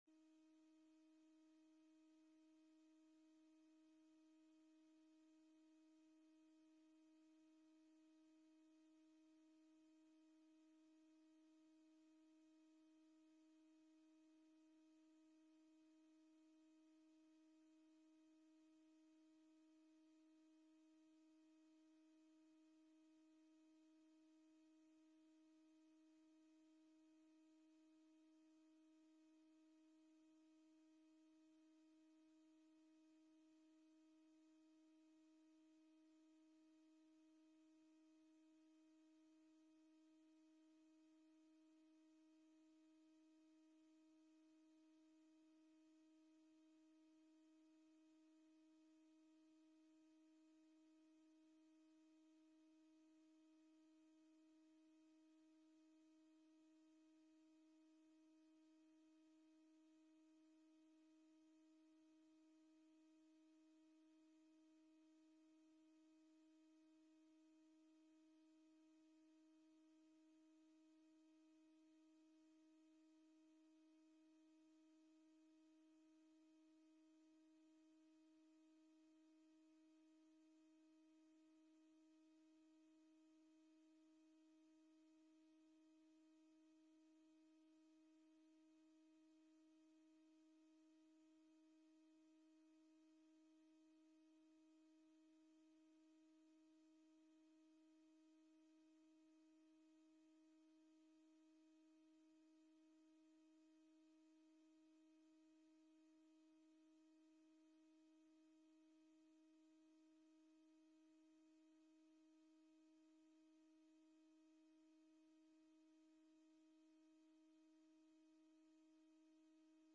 Agenda Nieuwegein - Avond van de Raad Digitaal vanuit Raadzaal donderdag 29 oktober 2020 20:00 - 22:10 - iBabs Publieksportaal
De vergadering wordt digitaal gehouden gezien de aangescherpte maatregelen.